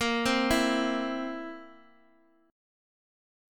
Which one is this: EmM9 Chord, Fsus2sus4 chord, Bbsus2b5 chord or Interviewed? Bbsus2b5 chord